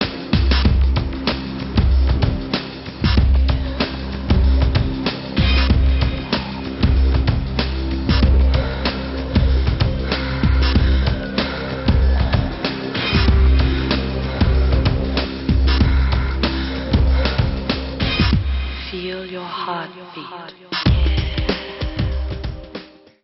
Recorded: A.R.T. Studios, Ibiza, Spain